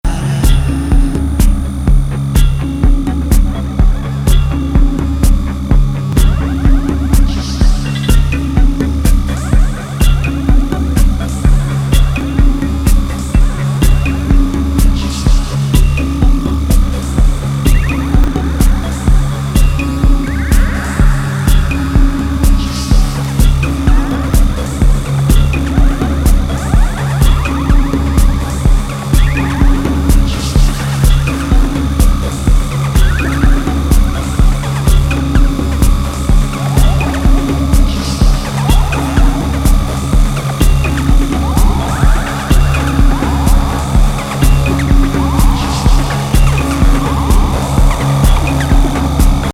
ELECTRO ROCK